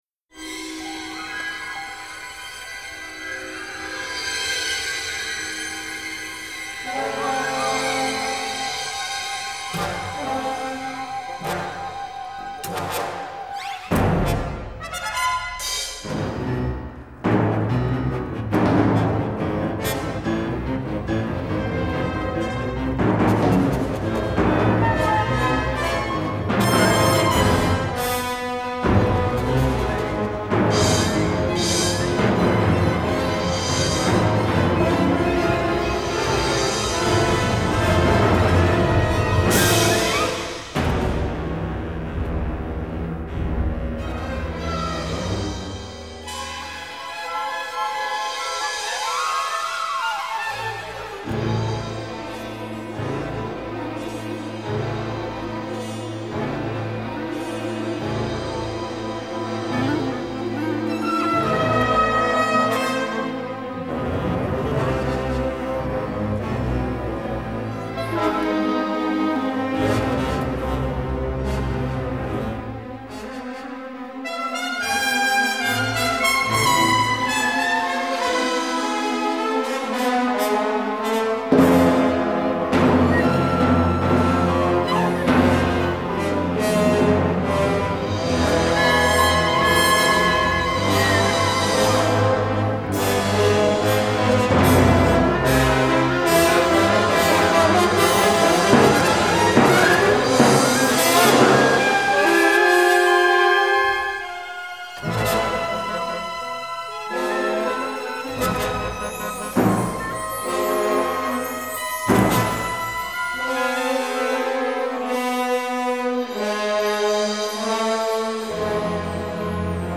Trumpets, French horns keep everything at peak excitement.
Recorded in Germany.